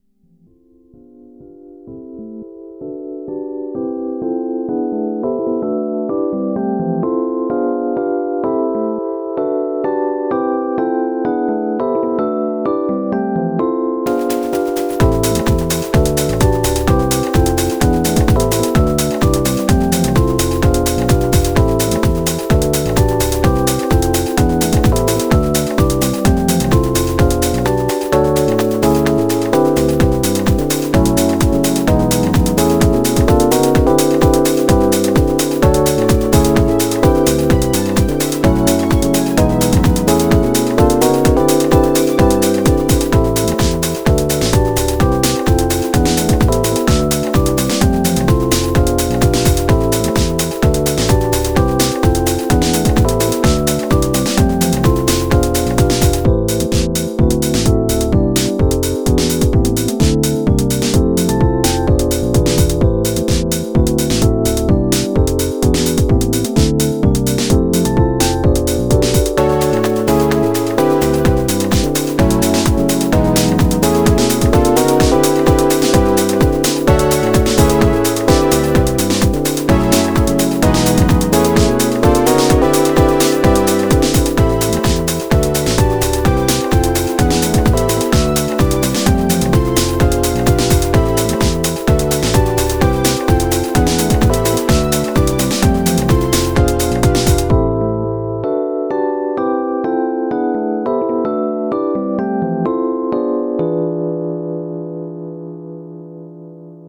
BPM128